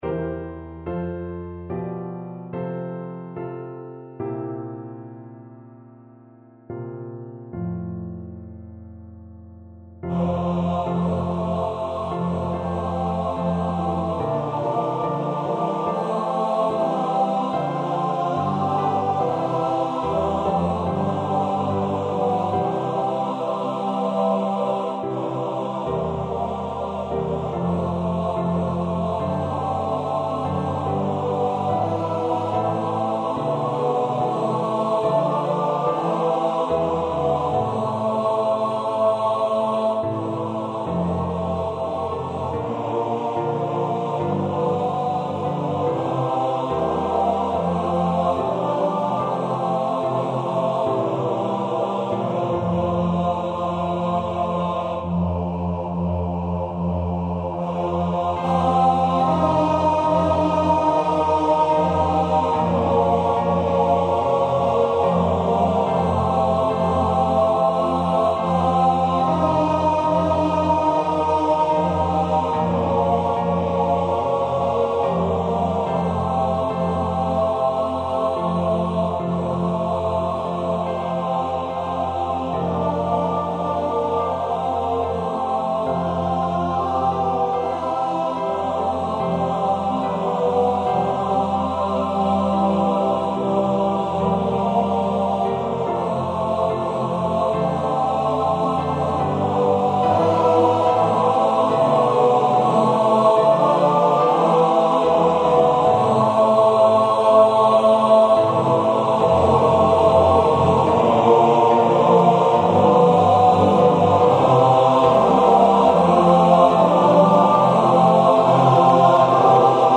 A powerful arrangement of the classic Navy Hymn